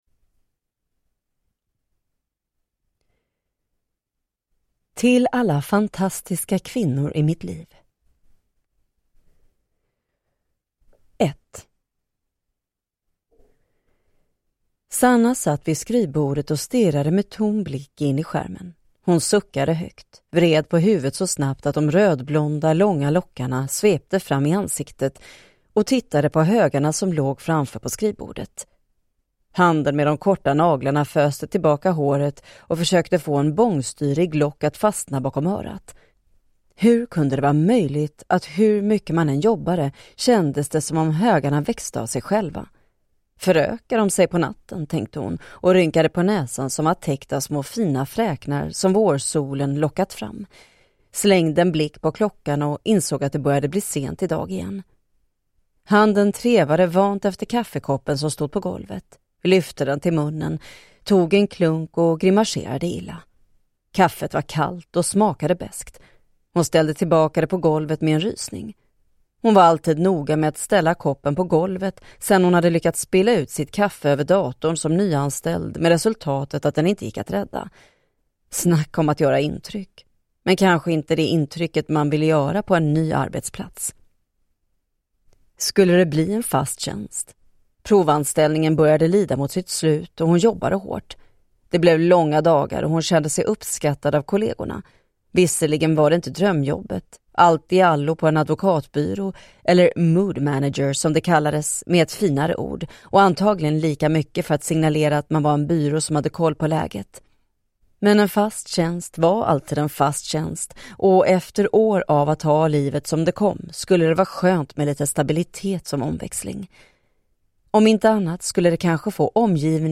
Svek, sol och sangria – Ljudbok